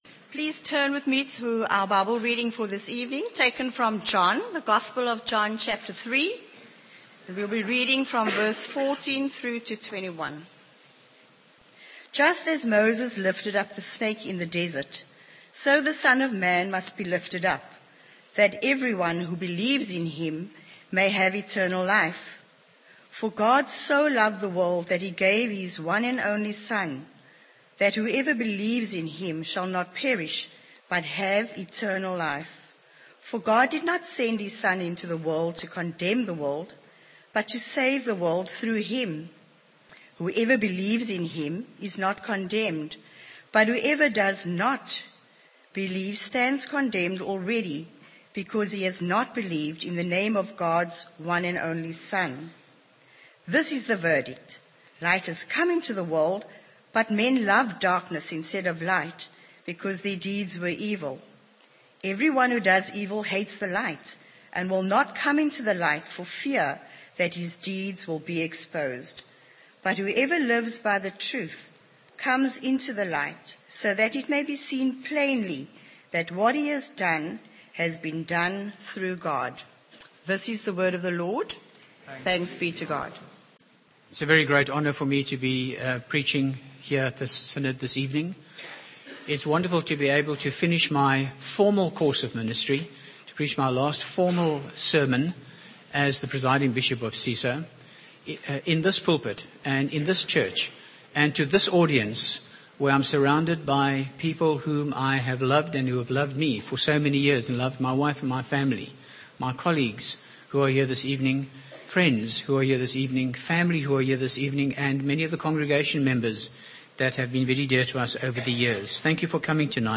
Synod 2010 serivce: The Love of God (John 3:14-21)
by Frank Retief | Jan 21, 2025 | Frank's Sermons (St James) | 0 comments